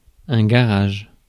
Ääntäminen
Synonyymit parking Ääntäminen France (Paris): IPA: [ɛ̃ ɡa.ʁaʒ] Tuntematon aksentti: IPA: /ɡa.ʁaʒ/ Haettu sana löytyi näillä lähdekielillä: ranska Käännöksiä ei löytynyt valitulle kohdekielelle.